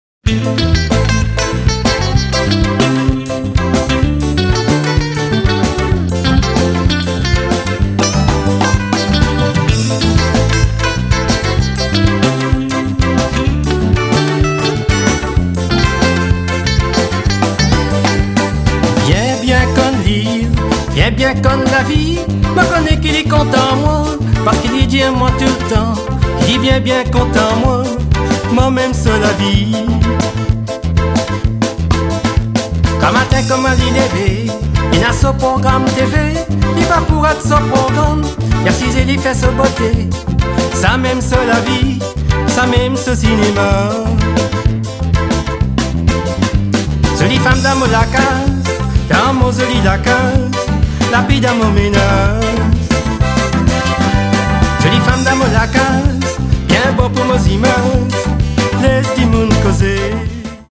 sax, guitars, bass, contrabass, clavier, sitar and violin